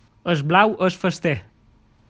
Qui la pronuncia: